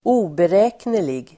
Ladda ner uttalet
oberäknelig adjektiv (mest om personer), unpredictable , erratic , capricious Uttal: [²'o:berä:knelig] Böjningar: oberäkneligt, oberäkneliga Synonymer: labil, nyckfull Definition: omöjlig att förutse; nyckfull